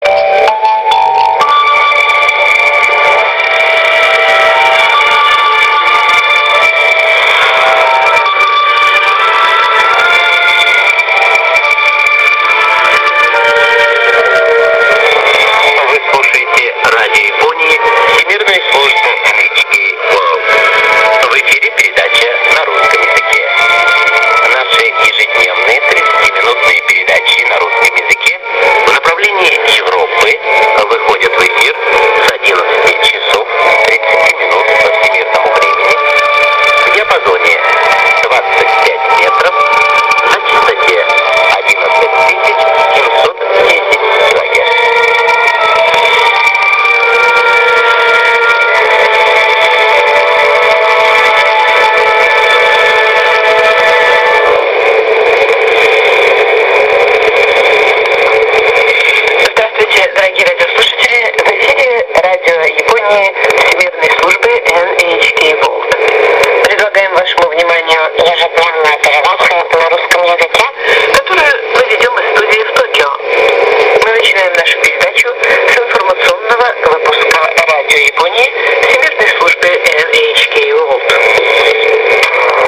Radio Japan Russian Service via Woofferton UK, 2002, 2003, Station IDs Audio